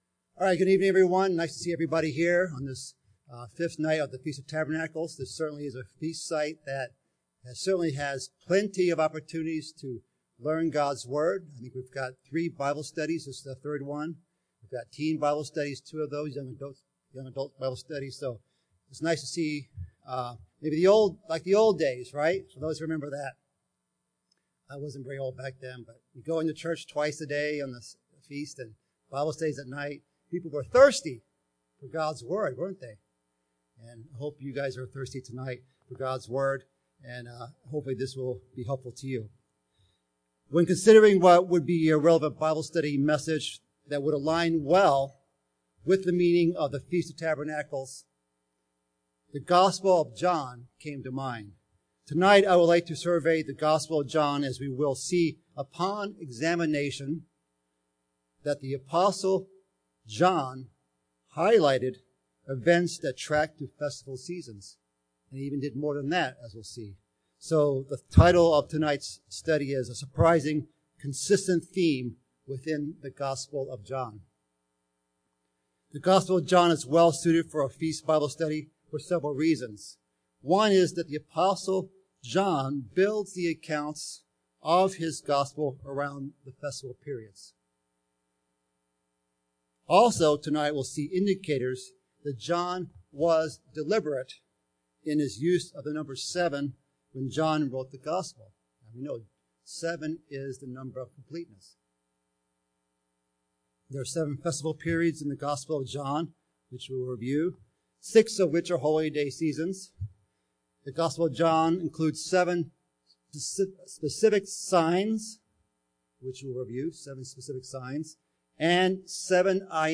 This sermon was given at the Pewaukee, Wisconsin 2020 Feast site.